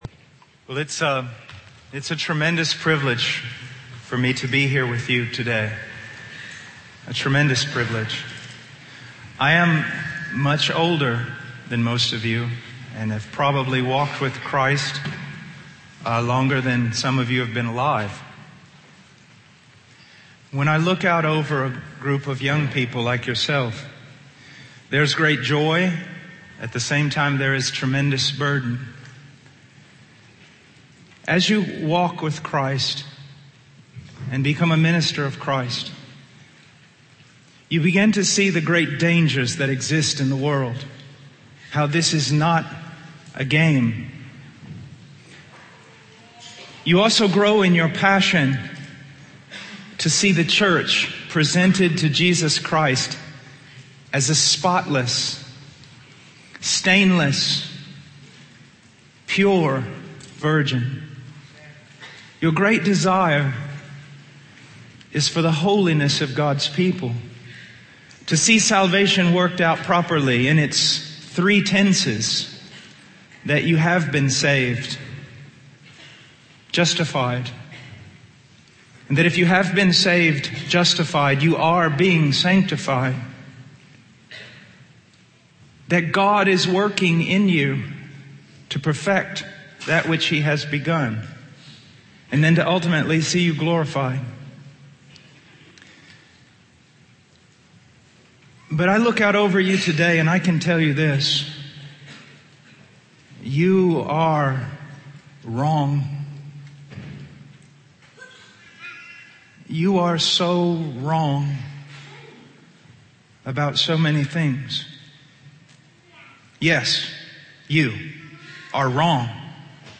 In this sermon, the preacher emphasizes the crushing and breaking of individuals, symbolized by a mass falling upon their heads.